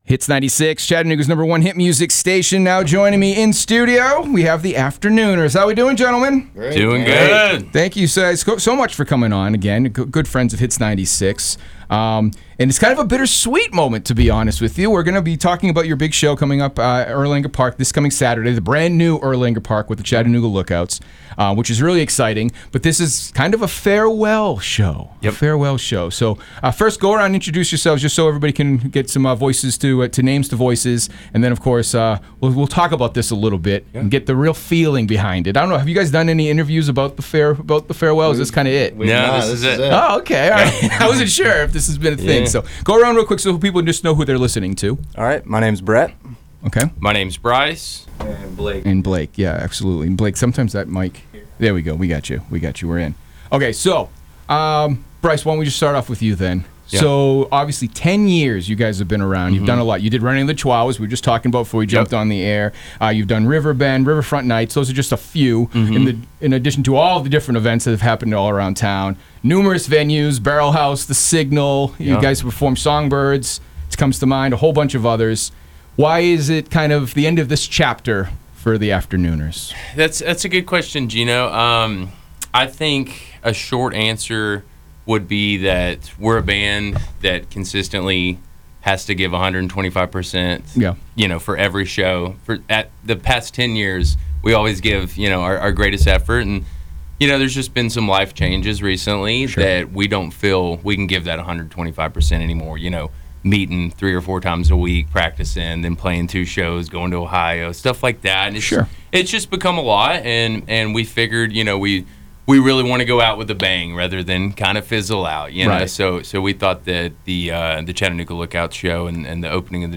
Reflecting on their journey, the band members expressed gratitude for the support and enthusiasm of Chattanooga’s music fans.